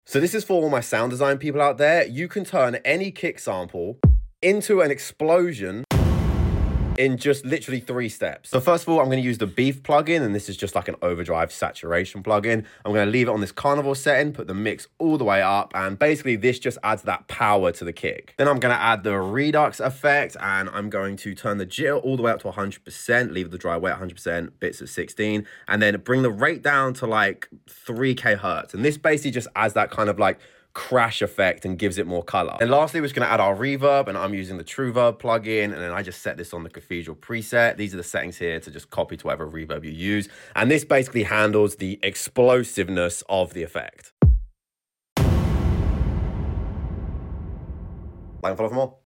Bonus tip: add a low pass filter and use it as an impact fx on your drops